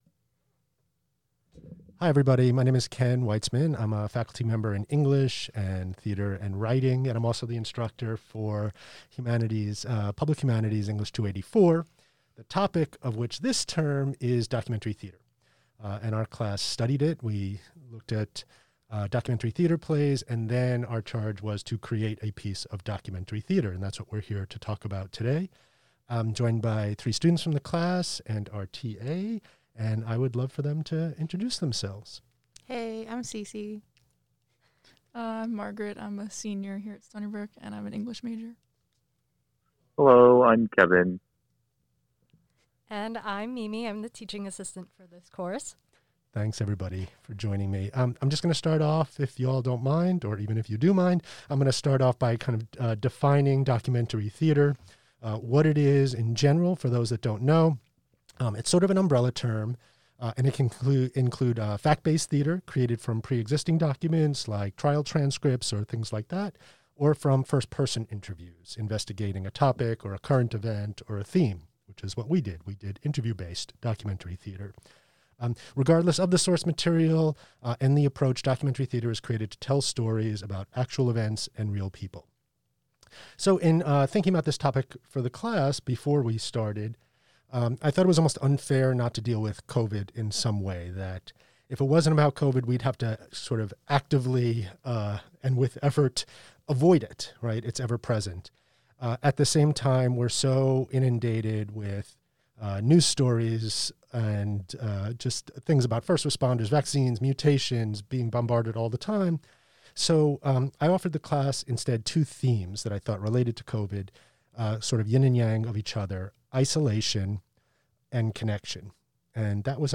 In this recorded conversation